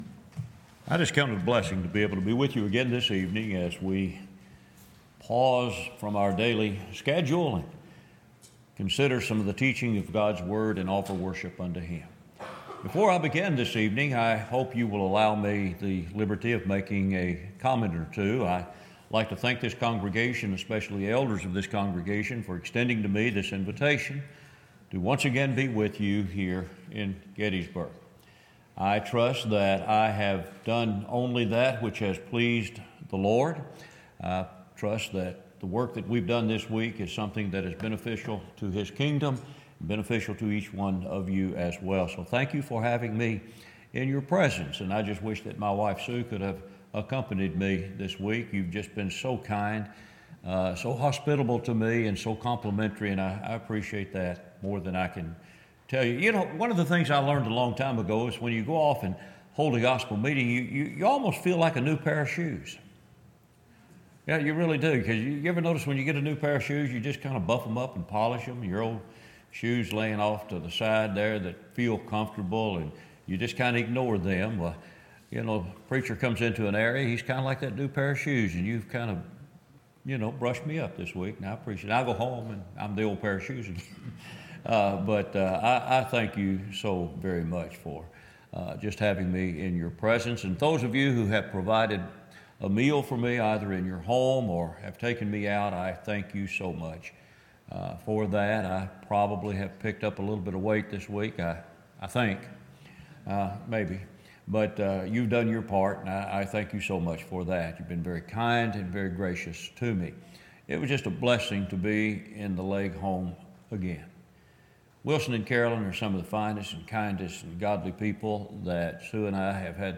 Series: Gettysburg 2019 Gospel Meeting